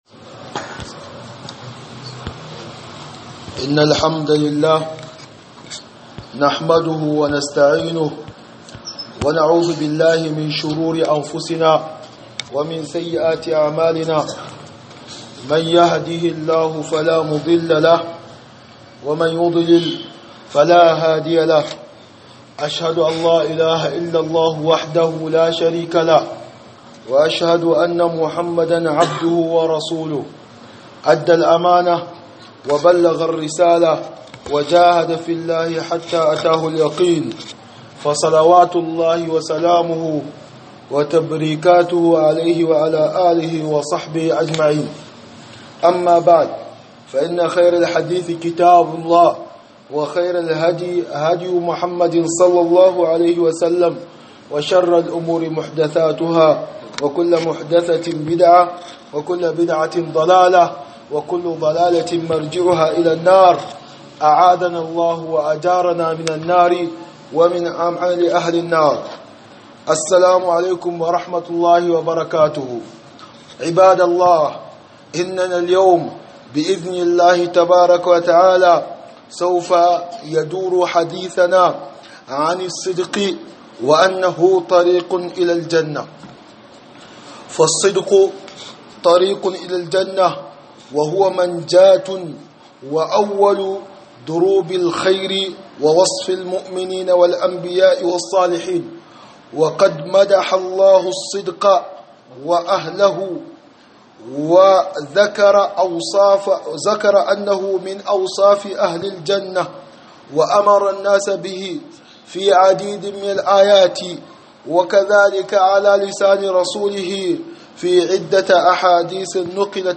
خطبة بعنوان الصدق طريق إلى الجنة